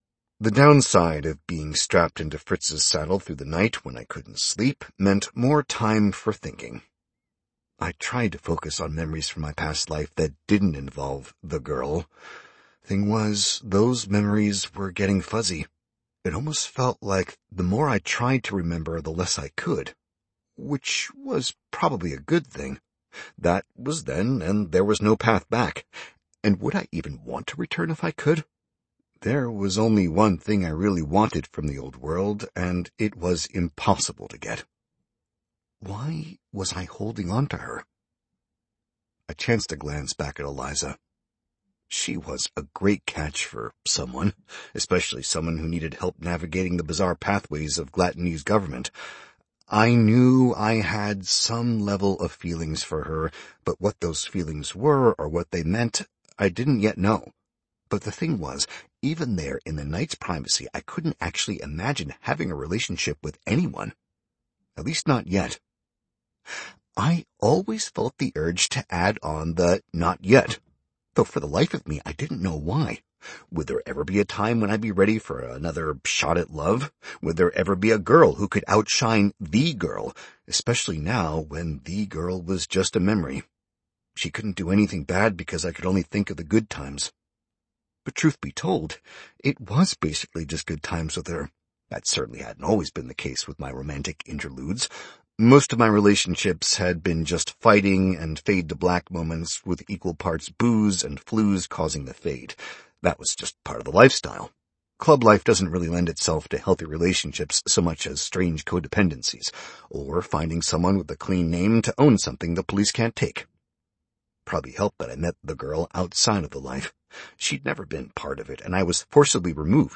Featured Releasesaudiobooks